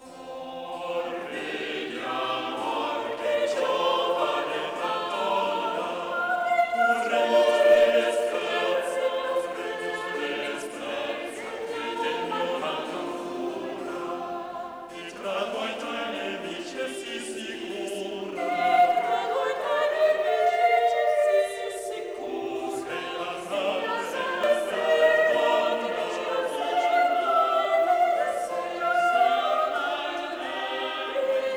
[ ill. 5: score "Hor vedi": four-part madrigal from Chansons et madrigales à quatre parties, Louvain, Phalèse, 1570, dedicated to Gerard of Groesbeck.